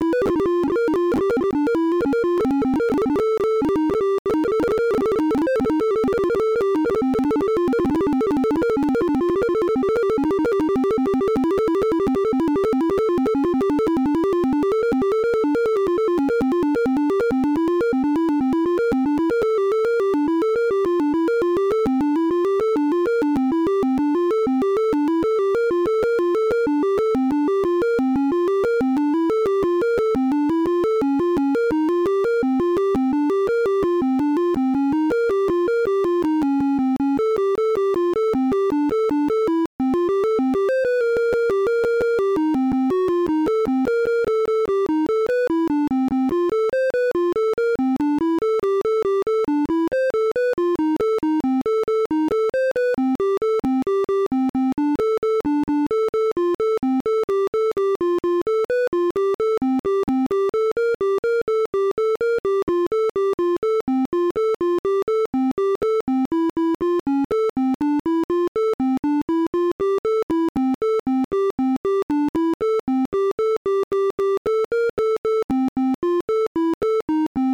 When playing real music this probably hardly matters, but in this example I'm deliberately keysmashing while in QWERTY and chromatic mode so what I'm composing is pure nonsense. Anyway I ended up entering 542 notes, and as you can see, at first it's really fast but then it gets slower and slower, and if I'd kept doing it, it'd get even slower. Note that I was banging on the keyboard fast during the whole recording.